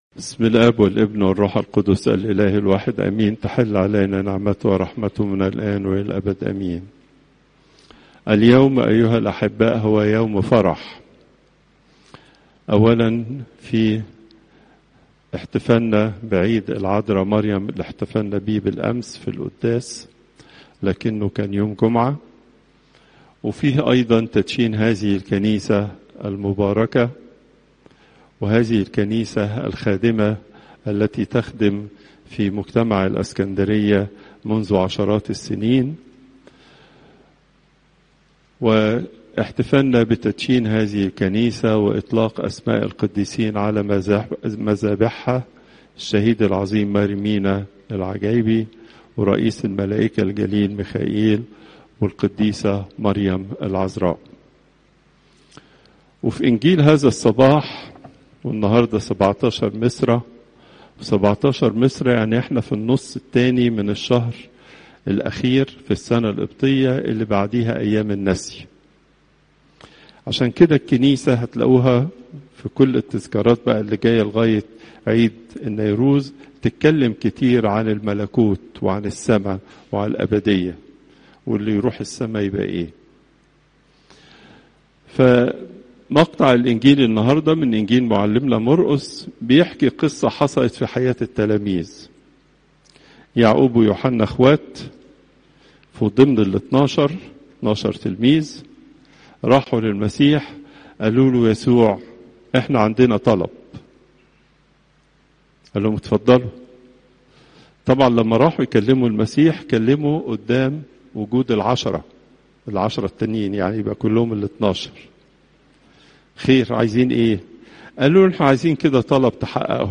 المحاضرة الأسبوعية لقداسة البابا تواضروس الثاني